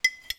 SFX_Spoon_02.wav